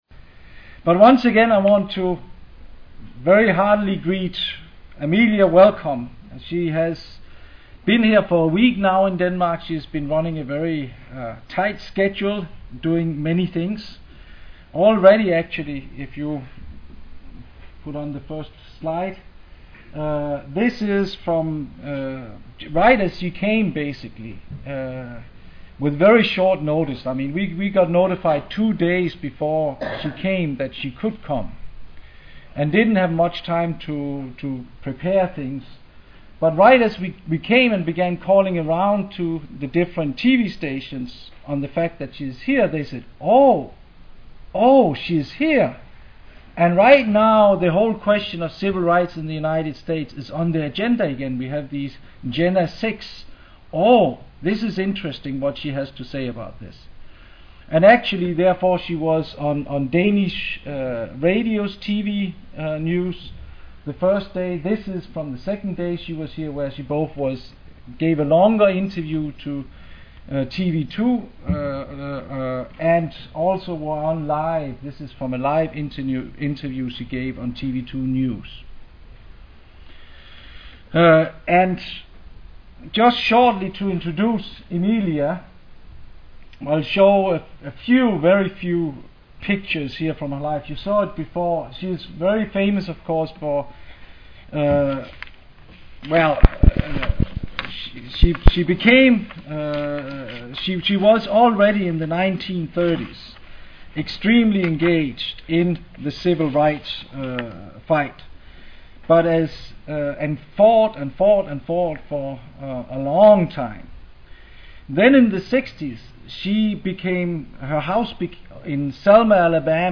The speech by Amelia Boynton Robinson (introduced by one of her poems) can be heard here.
Schiller Institute meeting with Amelia Boynton Robinson, civil rights leader and vice president of the USA Schiller Institute, in Frederiksberg-Hallen September 27, 2007.